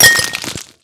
Break.wav